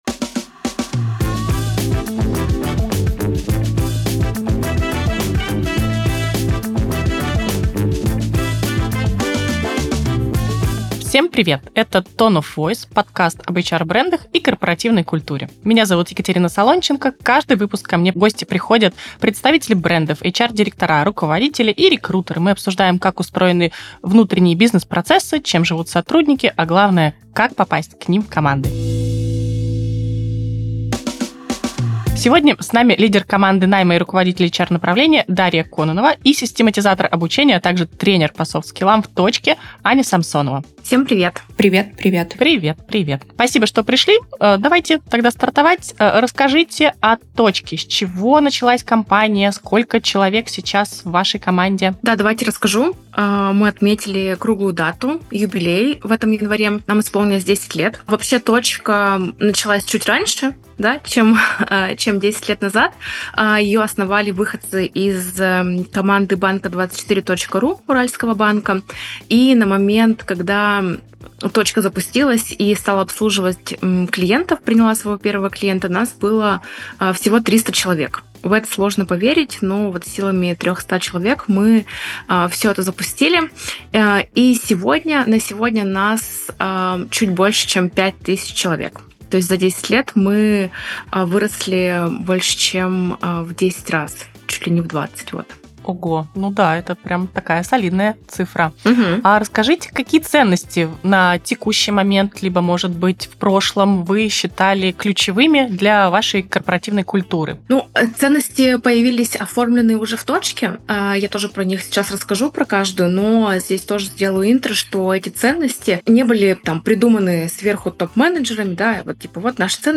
Ведущая приглашает в студию представителей брендов - HR-директоров, руководителей и рекрутеров. Вместе они обсуждают, как устроены внутренние бизнес-процессы, чем живут сотрудники, а главное - как стать частью их команды.